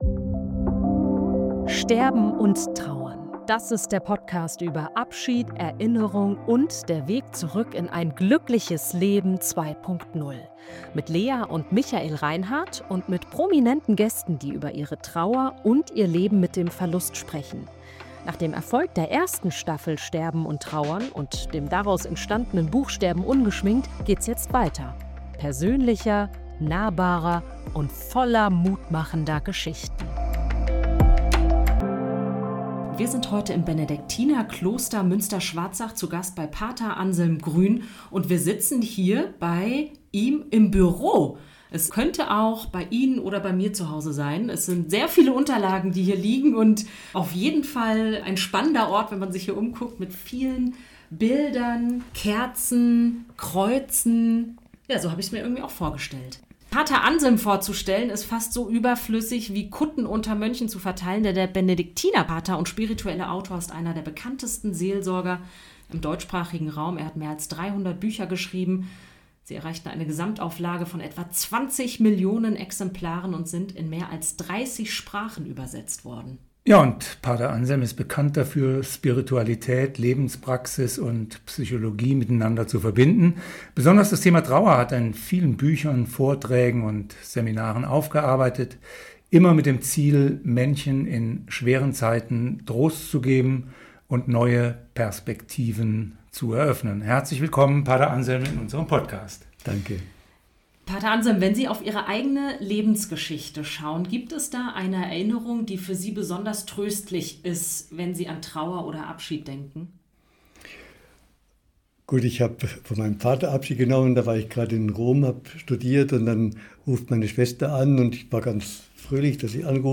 Diese Folge macht Mut: Trauer darf Zeit haben – und sie kann sich verwandeln. Ein Podcastgespräch über die Freiheit, dem Tod eine Bedeutung zu geben, über die bleibende Verbindung zu Verstorbenen – und über Hoffnung, die trägt.